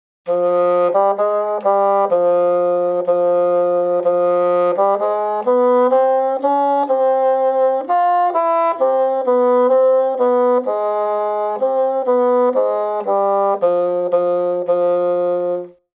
groep6_les1-3-3_fagot
groep6_les1-3-3_fagot.mp3